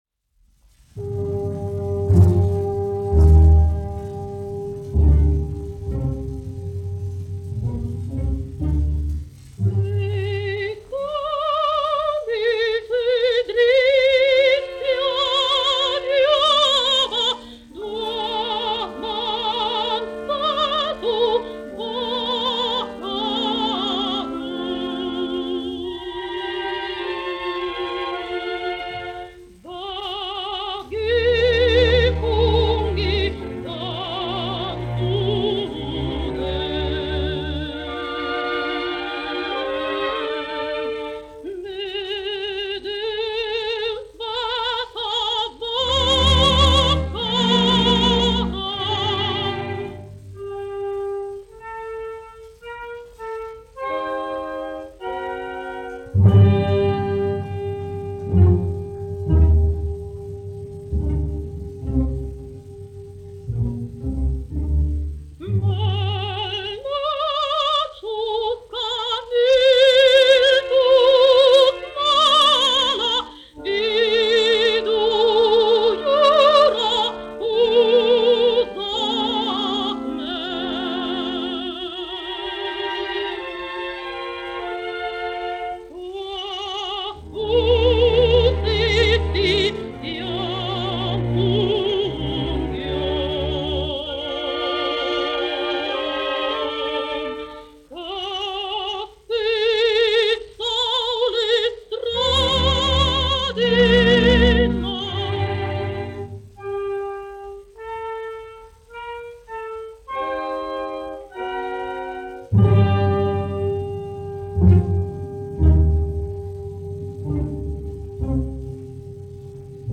1 skpl. : analogs, 78 apgr/min, mono ; 25 cm
Dziesmas (vidēja balss) ar orķestri
Latvijas vēsturiskie šellaka skaņuplašu ieraksti (Kolekcija)